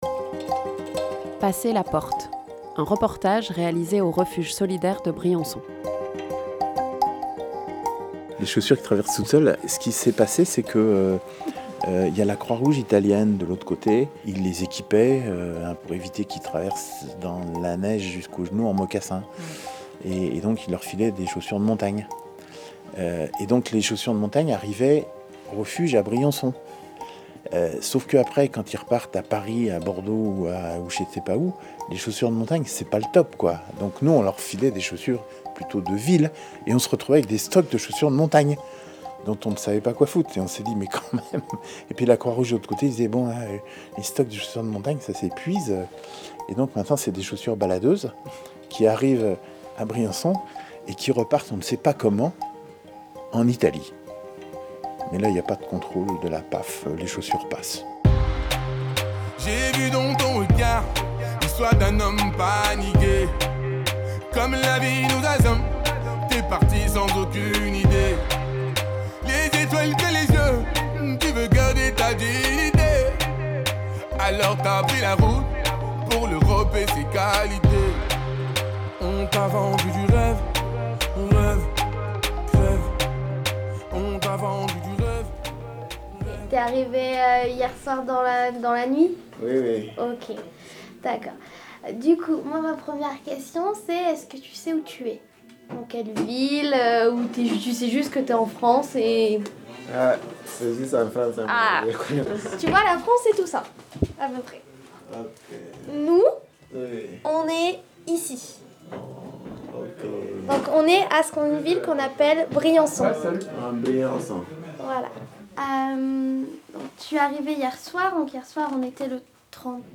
7 octobre 2019 17:01 | Interview, reportage
Nous avons passé du temps, avec ou sans micro, au refuge solidaire de Briançon. Migrant ou bénévole, comment passe-t-on la porte de ce lieu d’accueil juste en bas de la montagne ?
D’un côté les chants et musiques des migrants de passage qui ne souhaitent plus raconter leurs histoires aux médias, ne voyant pas de changement dans leurs conditions d’accueil. De l’autre une équipe bénévole à l’écoute, qui se demande toujours, deux ans après l’urgence, comment et pourquoi ils sont encore là.